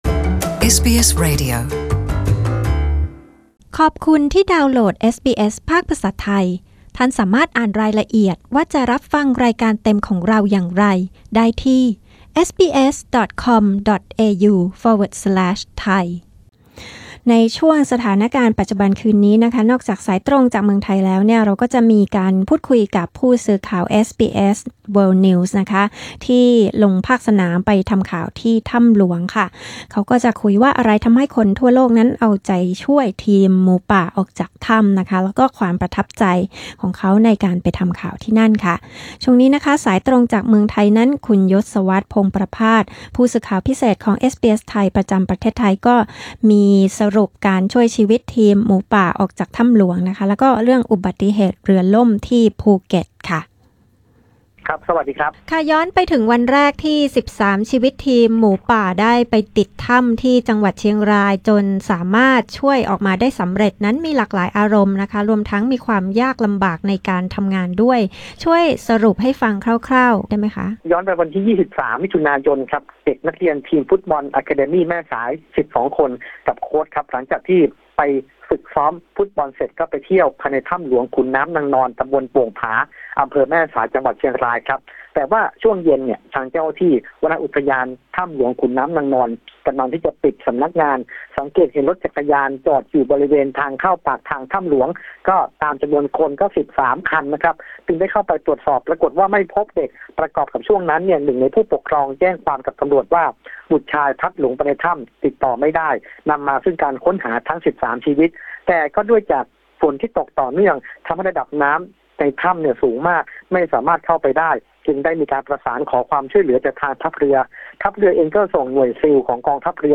รายงานข่าวสายตรงจากเมืองไทยวันที่ 12 ก.ค. 2018